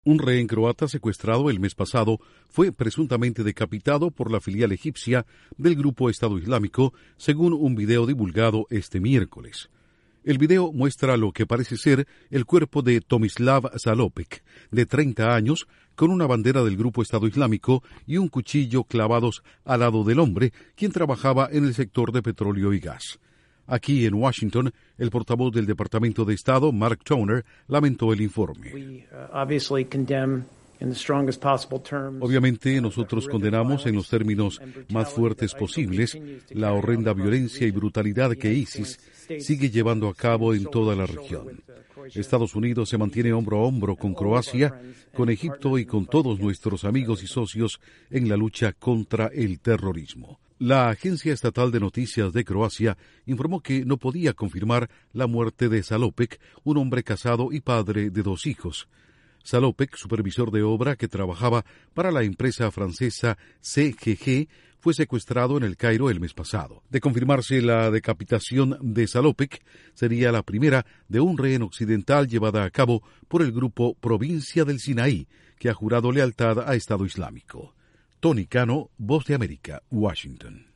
Estados Unidos condena enérgicamente la presunta decapitación de un rehén croata por parte del grupo Estado islámico. Informa desde la Voz de América en Washington